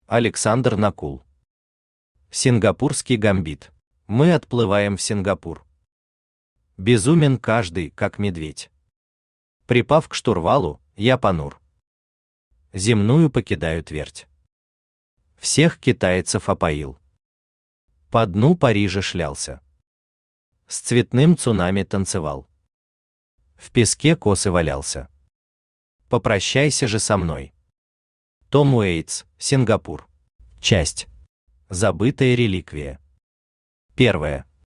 Аудиокнига Сингапурский гамбит | Библиотека аудиокниг
Aудиокнига Сингапурский гамбит Автор Александр Накул Читает аудиокнигу Авточтец ЛитРес.